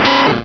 Cri de Rattatac dans Pokémon Rubis et Saphir.